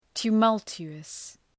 Προφορά
{tu:’mʌltʃu:əs}